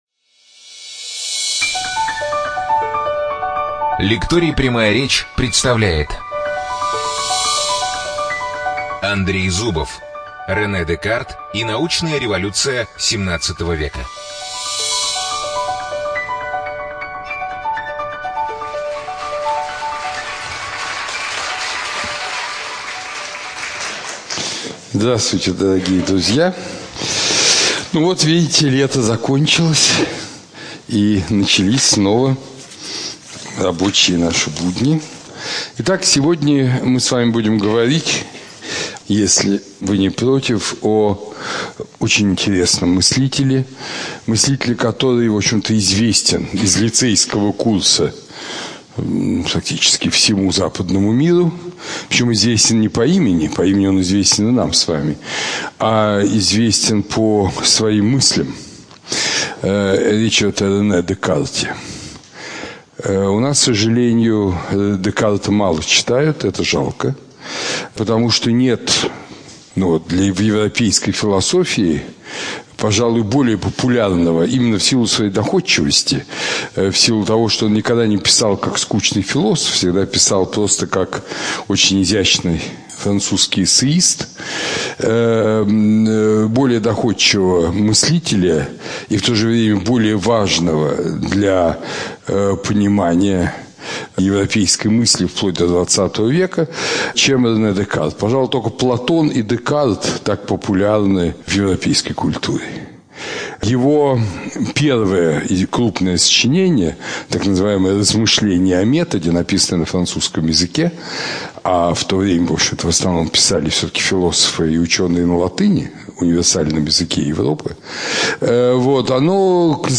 ЧитаетАвтор
Студия звукозаписиЛекторий "Прямая речь"